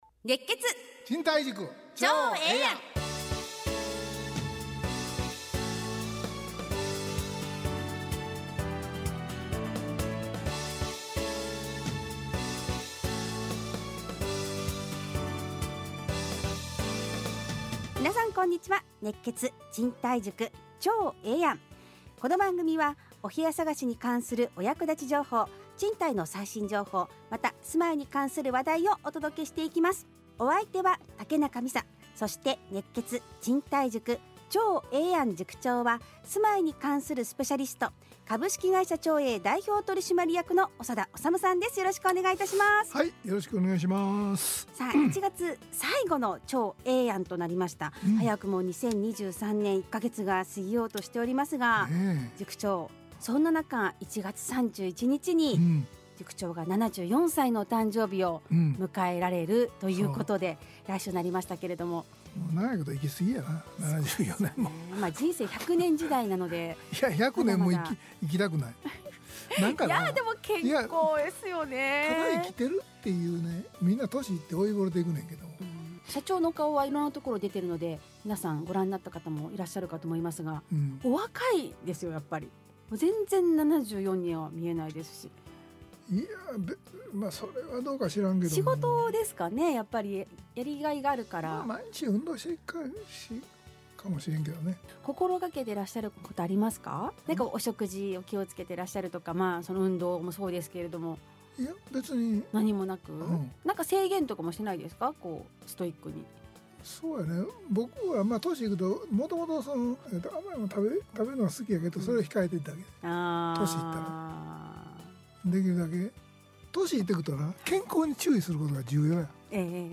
ラジオ放送 2023-01-30 熱血！